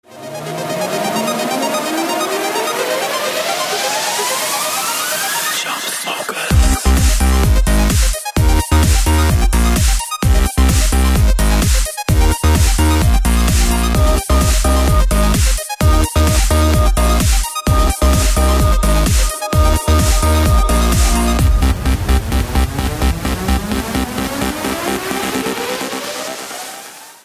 • Качество: 128, Stereo
мужской голос
громкие
remix
Electronic
электронная музыка
club
electro house